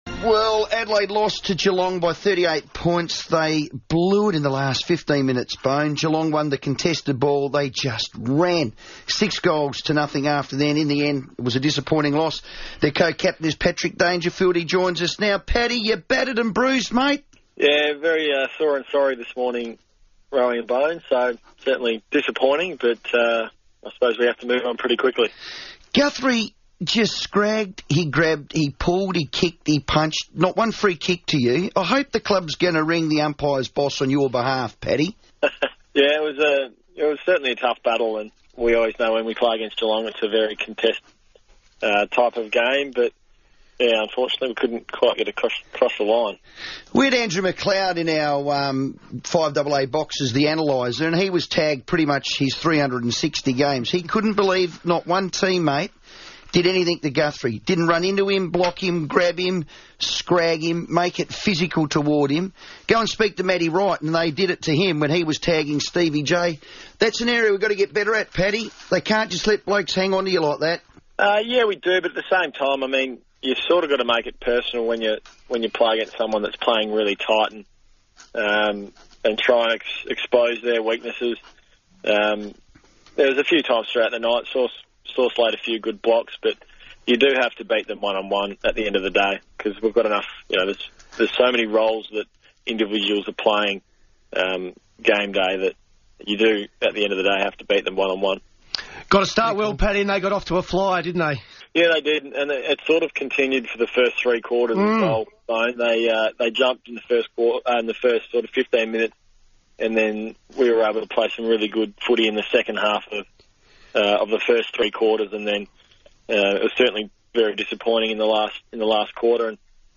Crows star Patrick Dangerfield was on radio station FIVEaa this afternoon